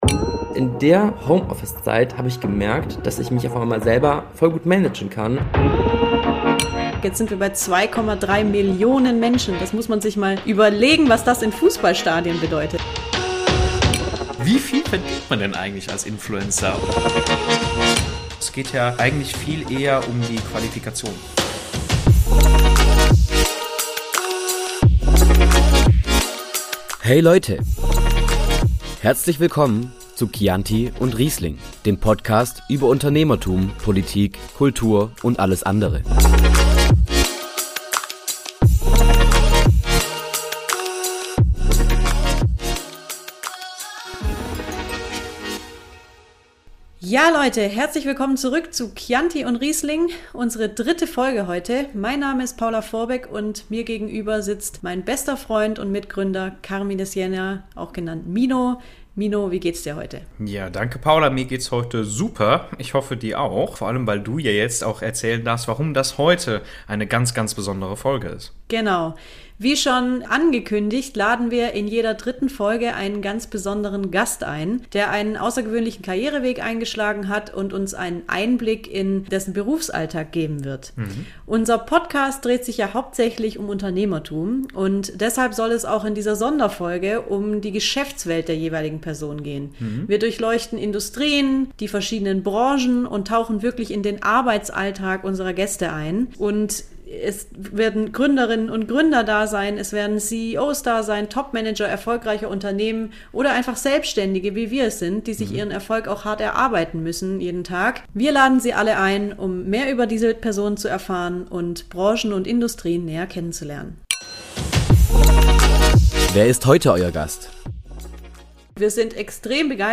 In dieser Sonderfolge von Chianti & Riesling sprechen wir mit unserem ersten Gast.